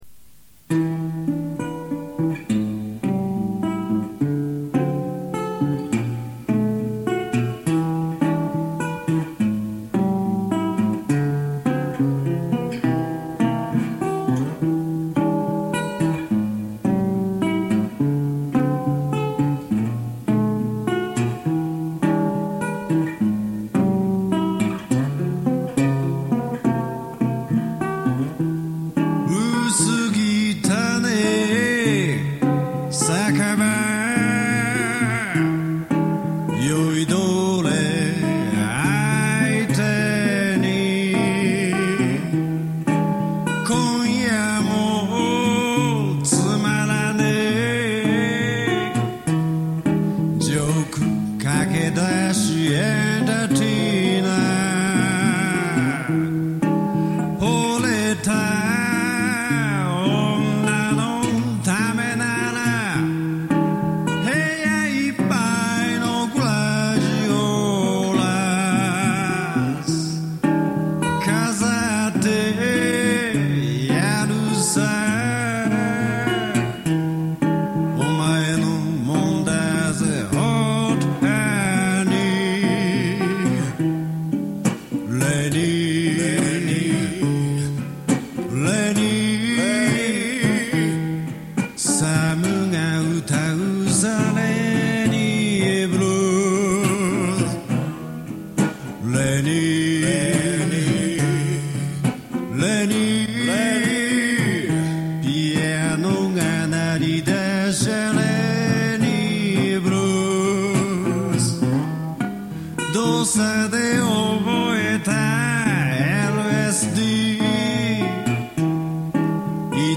某貸しスタジオに録音機材一式を持ち込み、録音。
ギターと歌だけなので、ｍｉｘが難しい。